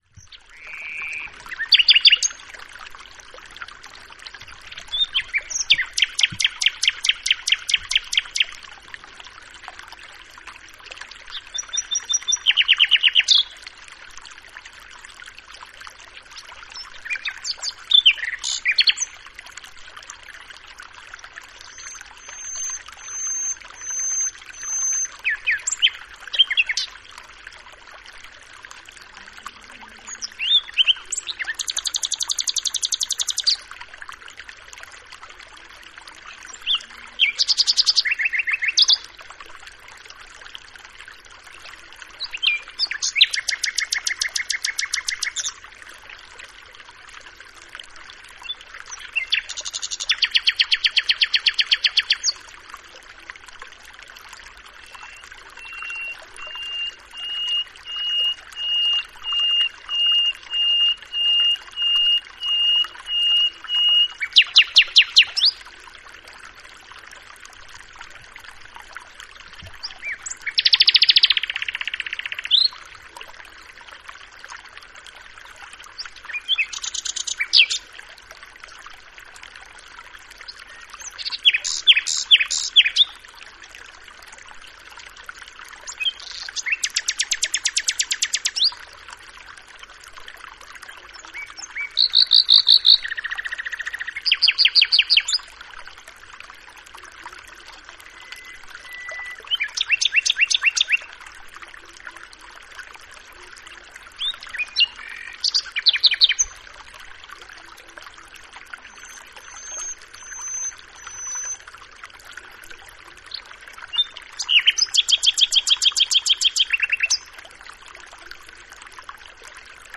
Nghtingale and running running water
Sleep+aid+nightingale+running+water.mp3